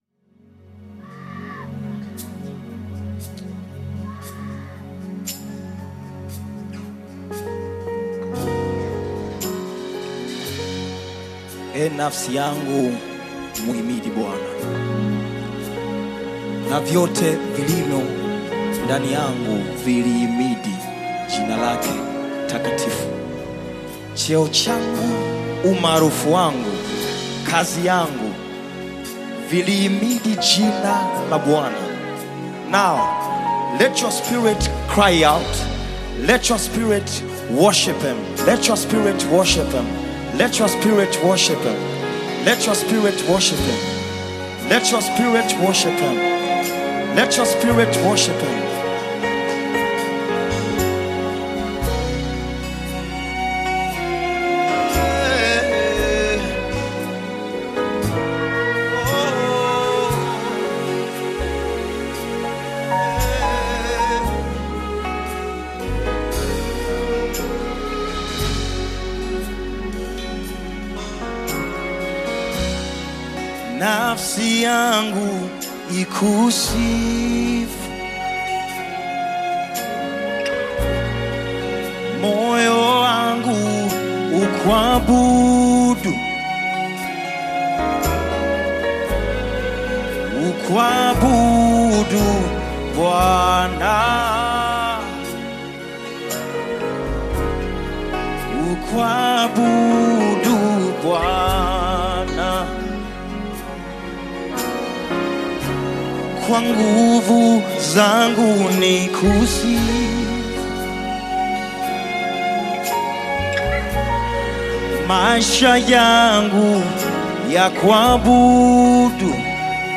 Nyimbo za Dini Worship music
Worship Gospel music track